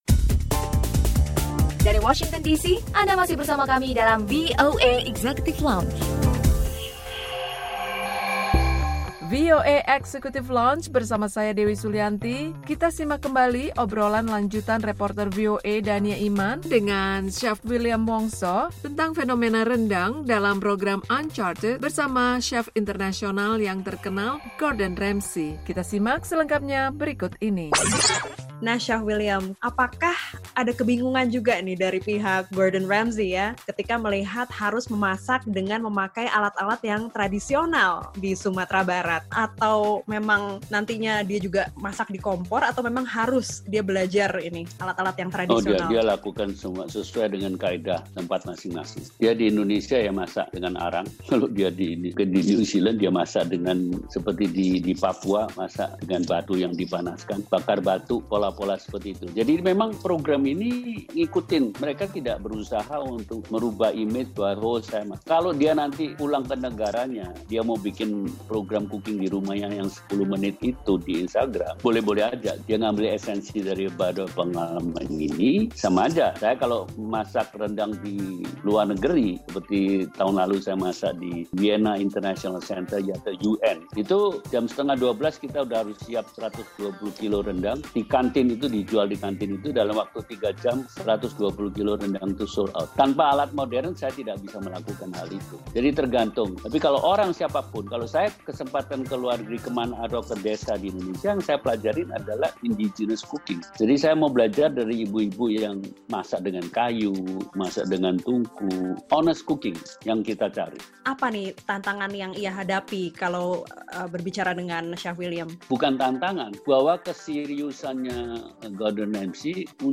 Obrolan lanjutan